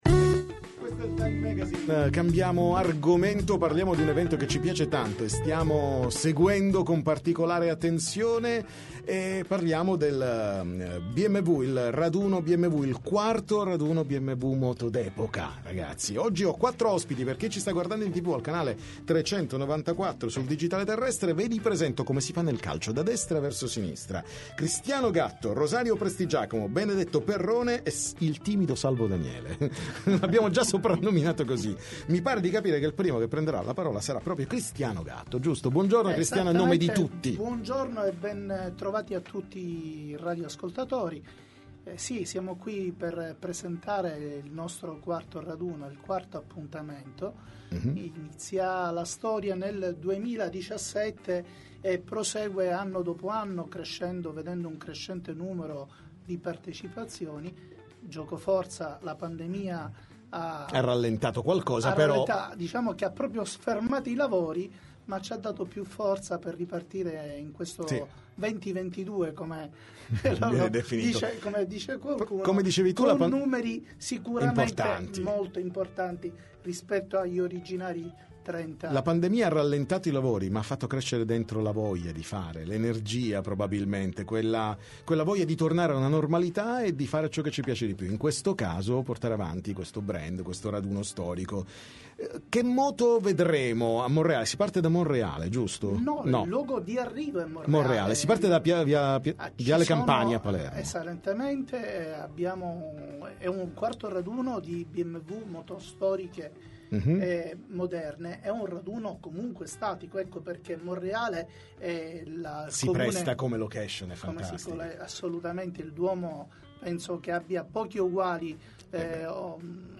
TM Intervista Bmw